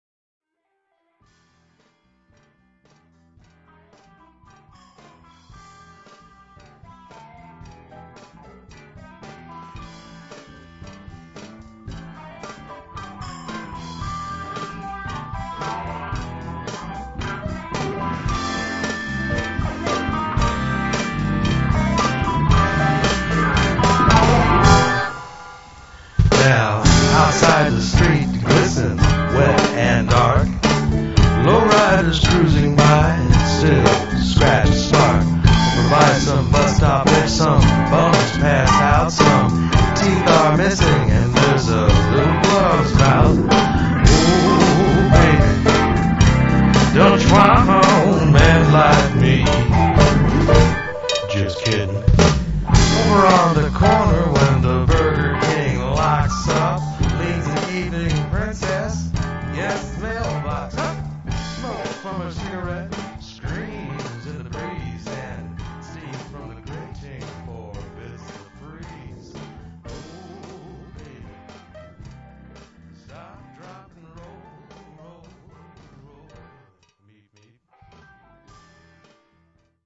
juskiddin-fades-echo.wav